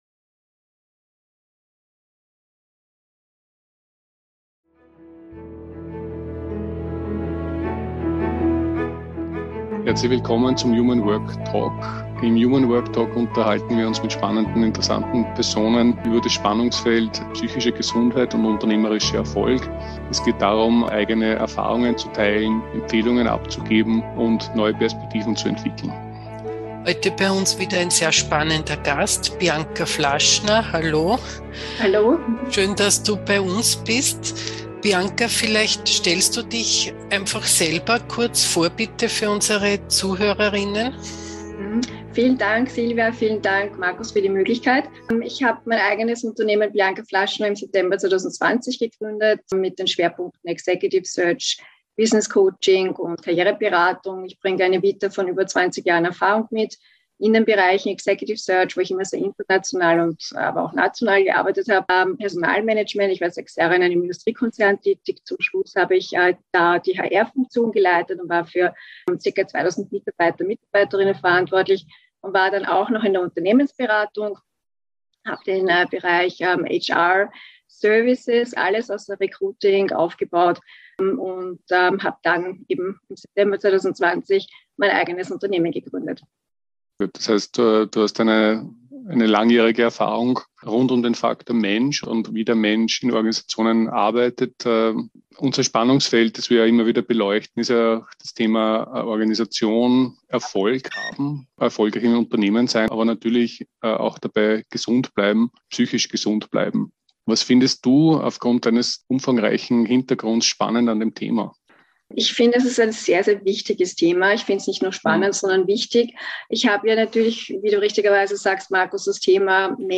Der Talk gibt fundierte Anregungen insbesondere für im HR-Bereich Tätige sowie für Führungskräfte, denen eine gesunde Unternehmenskultur ein Anliegen ist. Im human work Talk sprechen wir mit spannenden Gästen über ihre Erfahrungen im Spannungsfeld psychische Gesundheit und unternehmerischer Erfolg.